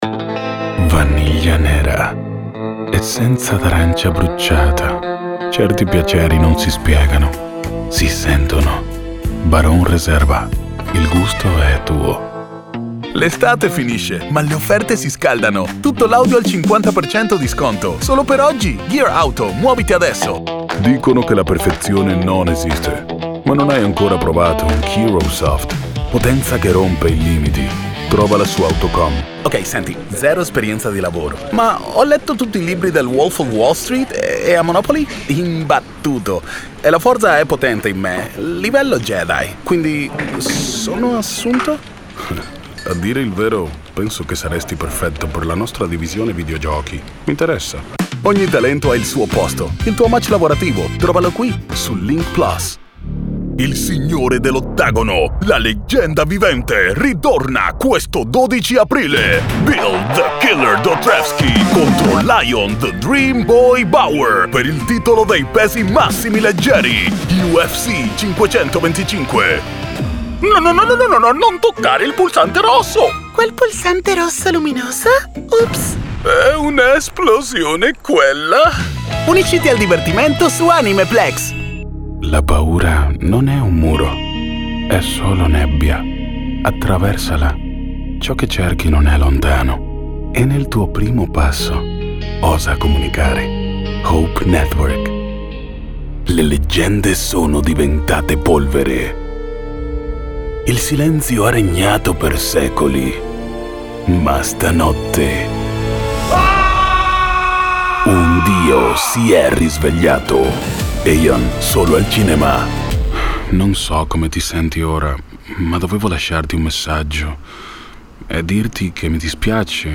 2026 Italiano Demo Di Voce
Demo Di Voce 2026.mp3